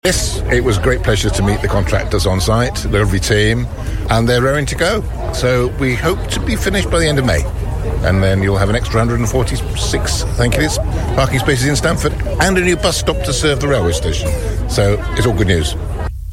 Portfolio holder for Property, Councillor Richard Cleaver, was there to meet them.